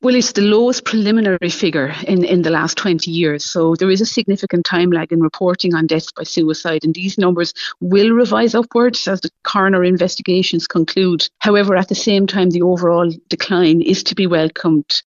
Mental Health Minister Mary Butler, says she’s determined to drive the number down further…………….